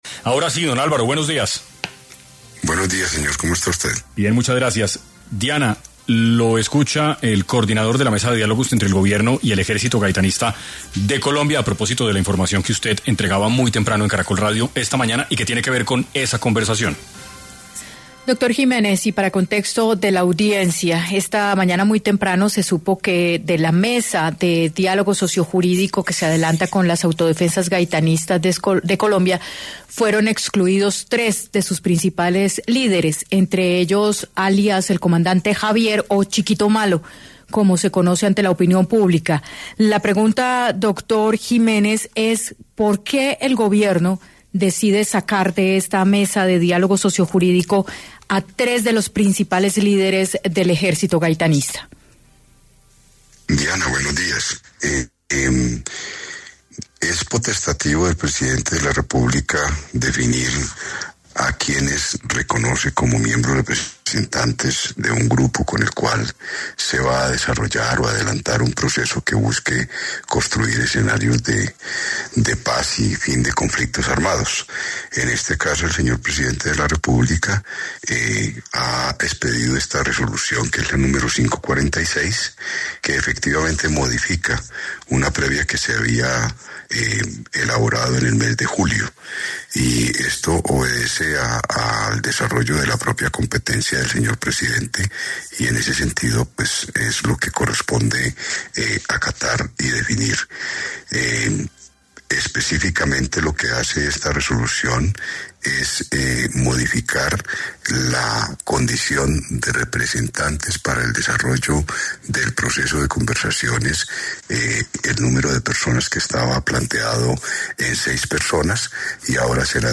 Por tal motivo, en 6AM estuvo Álvaro Jiménez Millán, coordinador de la Mesa de diálogos entre el Gobierno y el Ejército Gaitanista de Colombia, para hablar sobre este tema y las sensaciones que se tienen desde el grupo armado sobre este tema.